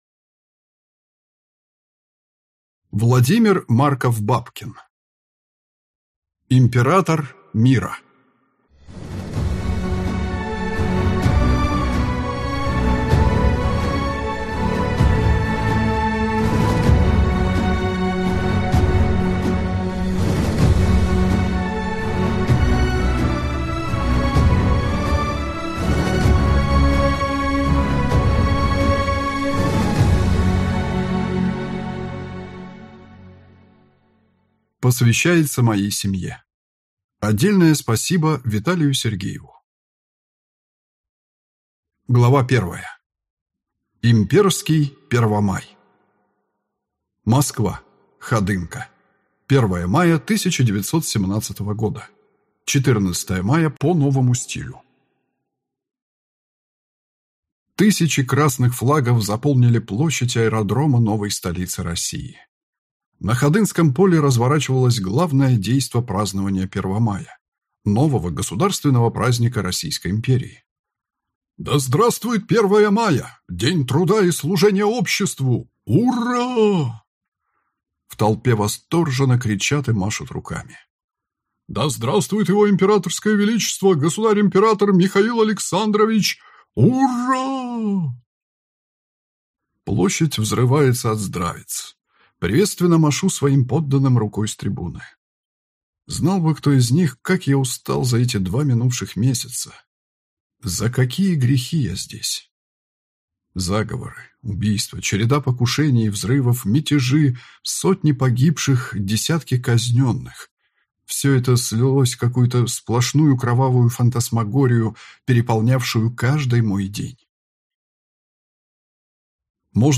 Аудиокнига Император мира | Библиотека аудиокниг
Прослушать и бесплатно скачать фрагмент аудиокниги